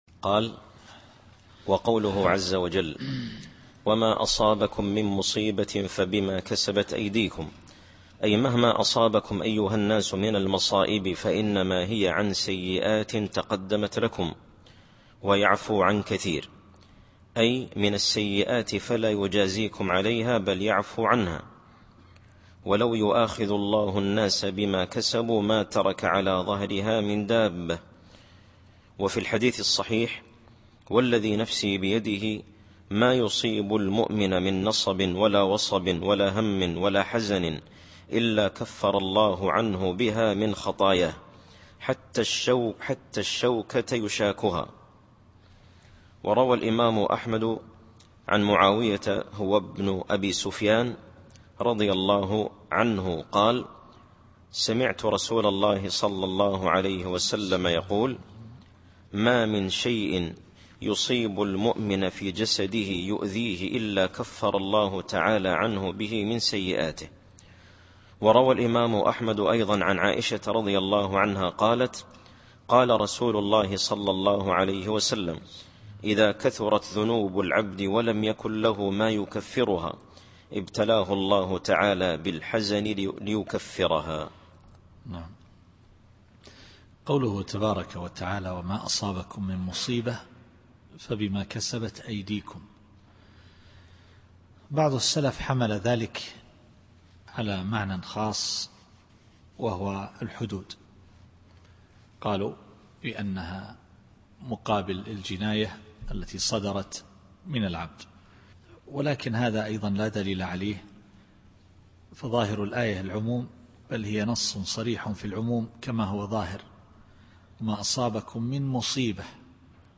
التفسير الصوتي [الشورى / 30]